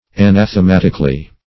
Definition of anathematically.